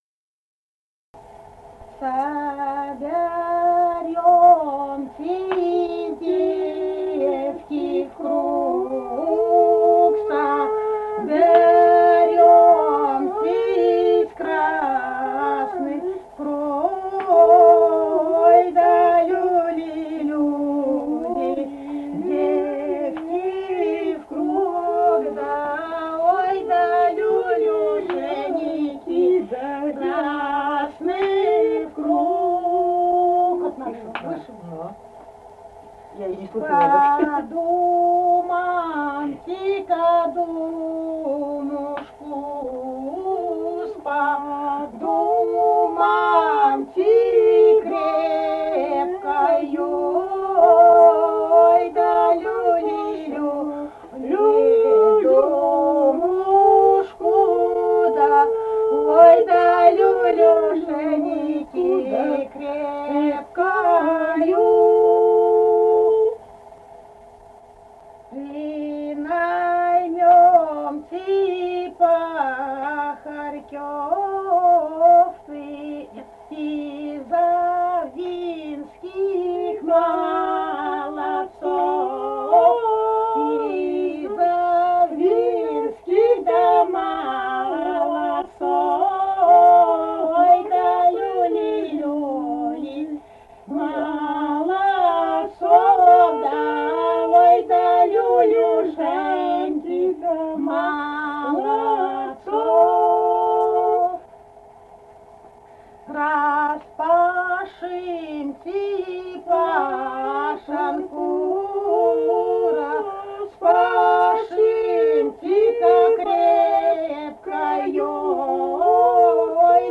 Республика Казахстан, Восточно-Казахстанская обл., Катон-Карагайский р-н, с. Язовая, июль 1978.